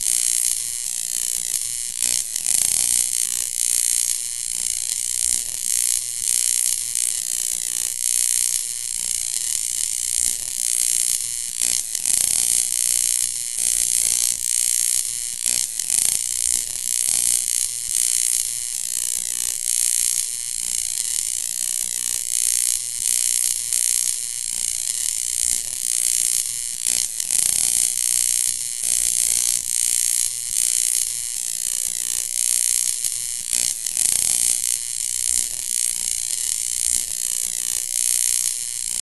ジージージーと電気が点滅するイメージの効果音。